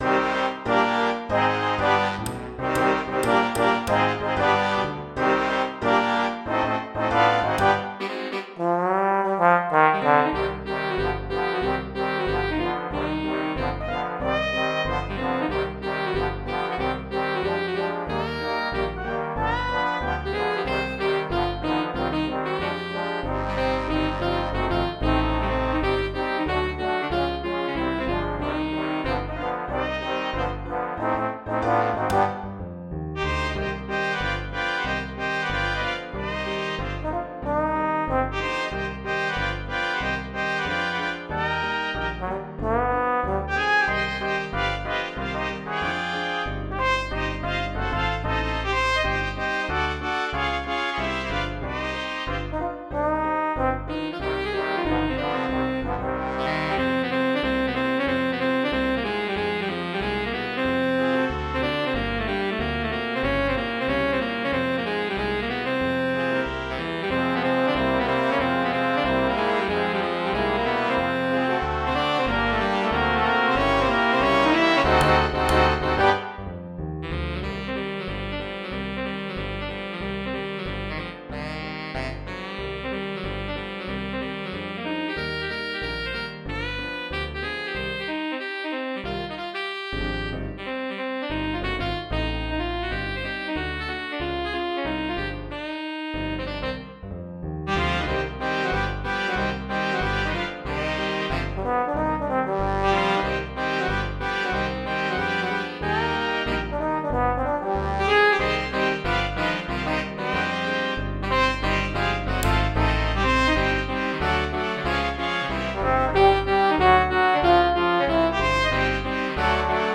For 17-piece Jazz Band